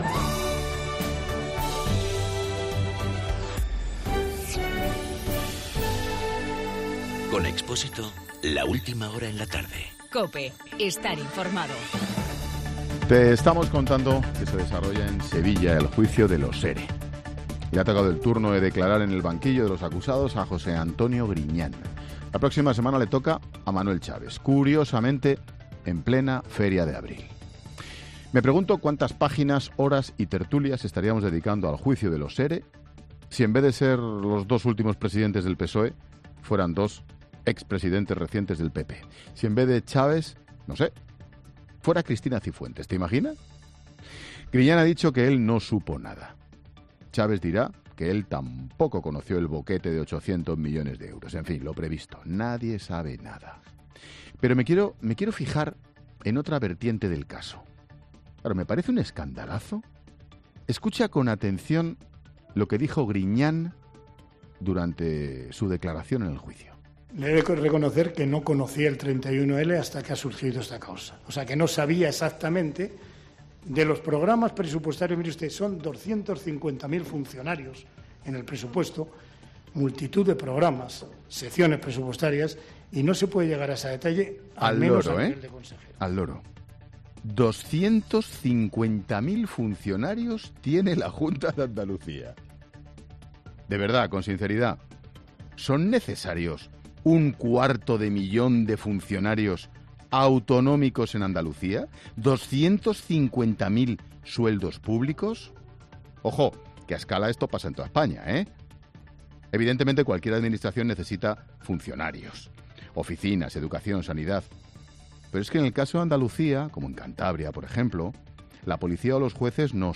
Monólogo de Expósito
El comentario de Ángel Expósito sobre el juicio de los EREs.